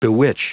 Prononciation du mot bewitch en anglais (fichier audio)
Prononciation du mot : bewitch